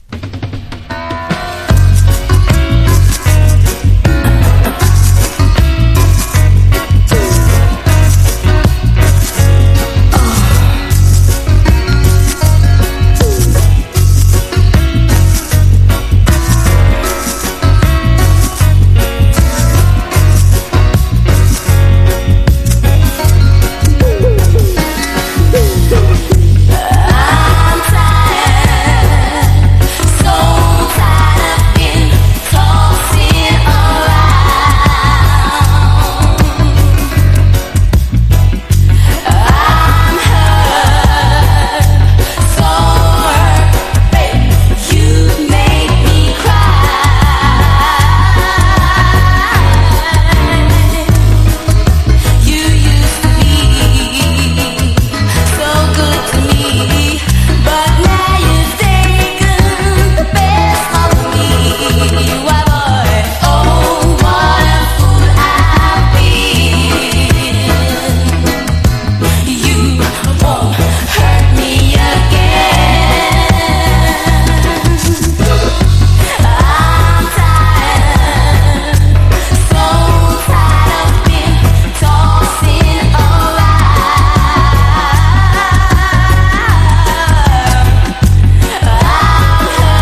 • REGGAE-SKA